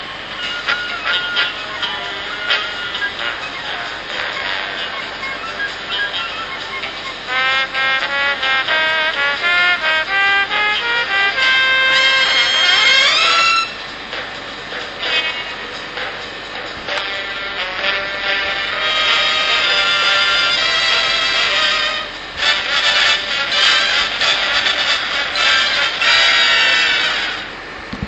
End Theme (Saxophone)